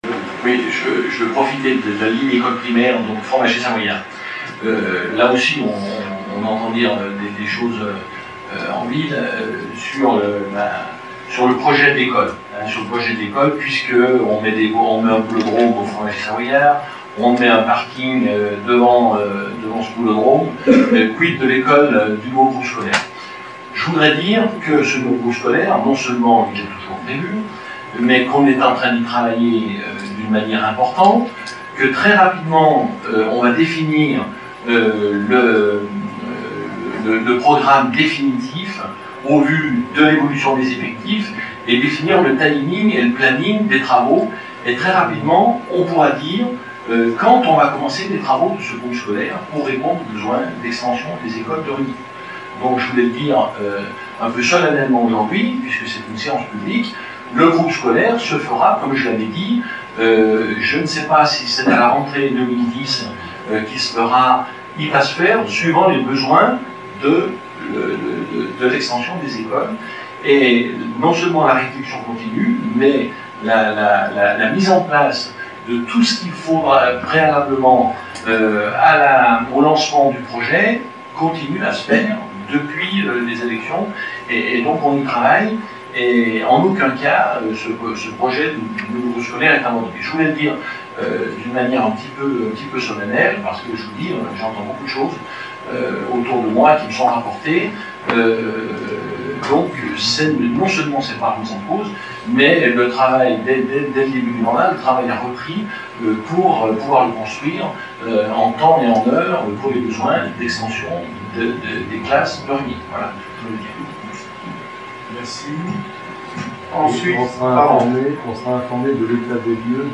Extrait audio du conseil municipal du 22/05/2008 sur le centre scolaire Juillet 2007 .